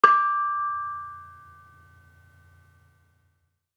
Bonang-D5-f.wav